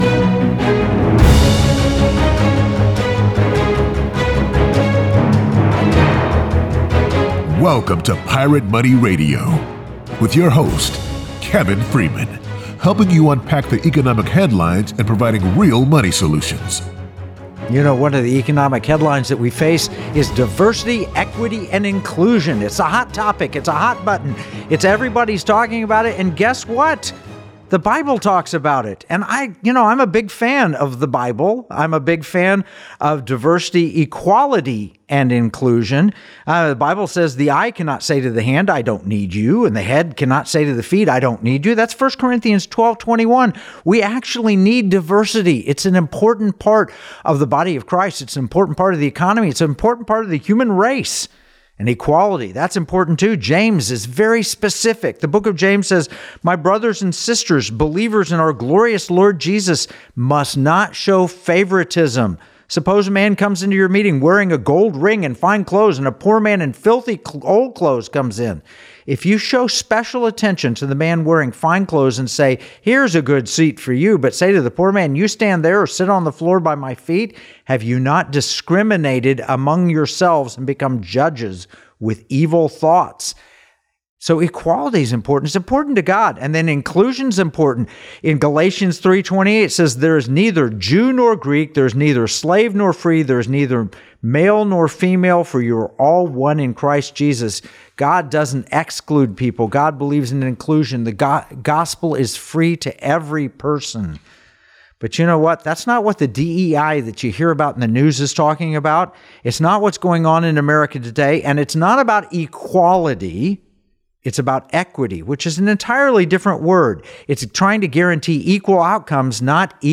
They critique DEI's failure to deliver on its promises, argue for merit-based recognition and performance, and discuss alternatives that promote genuine diversity and inclusion without compromising individual liberties and values. Tune in for a thought-provoking discussion that challenges the narrative and presents actionable ways forward, aligning with liberty, security, and values.